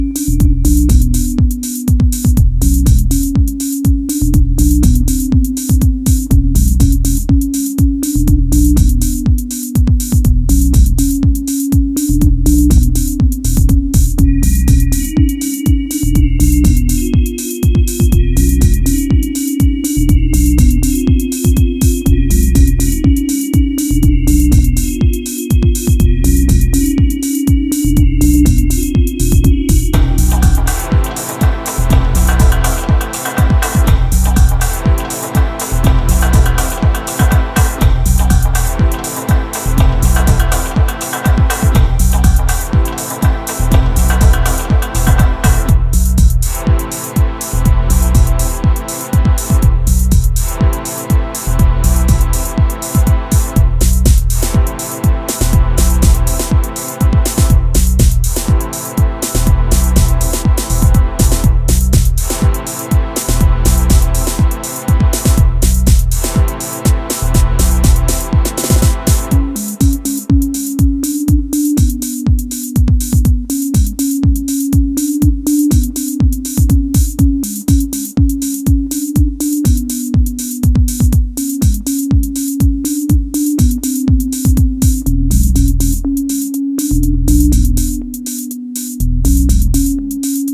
NYC deep house